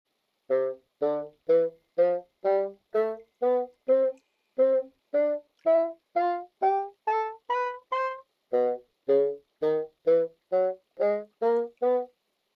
Brusque_Bassoon_Sound.mp3